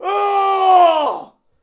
battle-cry-6.ogg